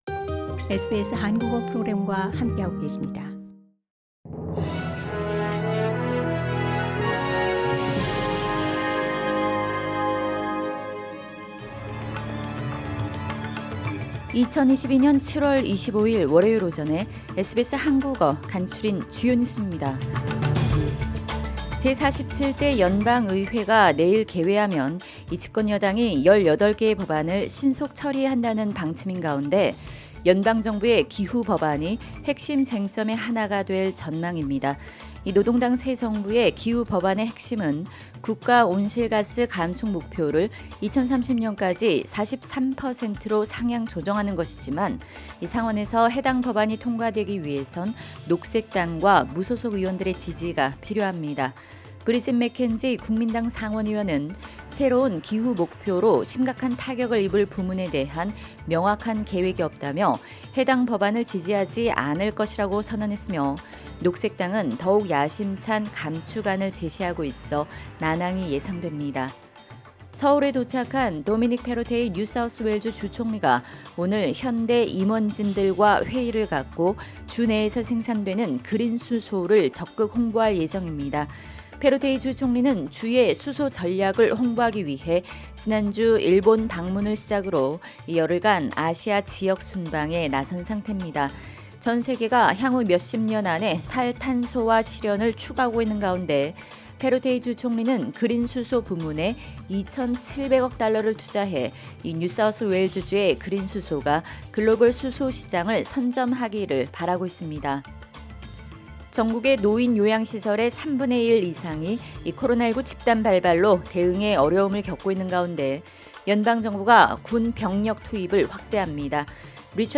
SBS 한국어 아침 뉴스: 2022년 7월 25일 월요일